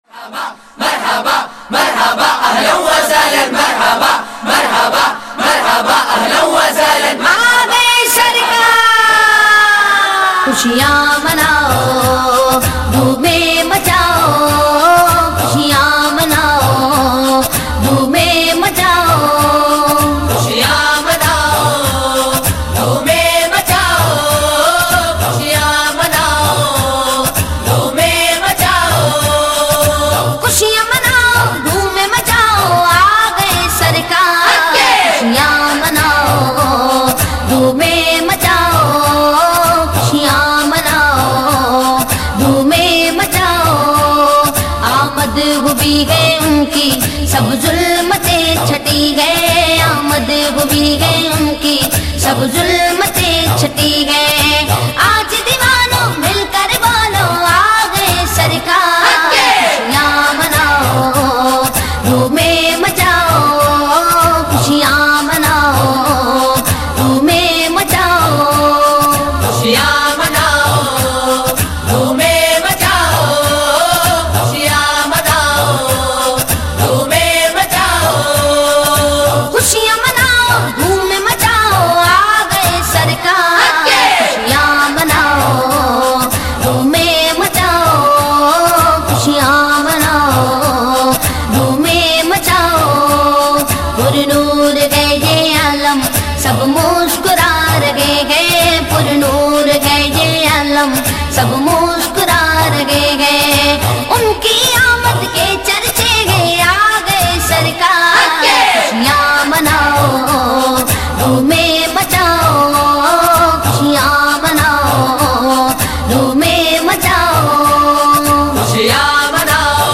a naat sharif of welcome and joy